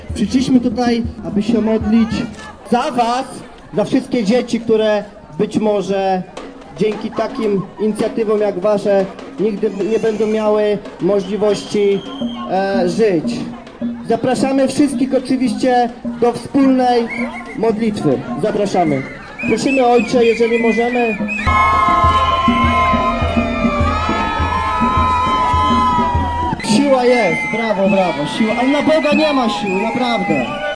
Jednocześnie została zorganizowana przeciwna manifestacja - w obronie życia. - Przyszliśmy, aby się modlić za was, za wszystkie dzieci, które być może dzięki takim inicjatywom jak wasze, nigdy nie będą miały możliwości żyć - padło z głośników.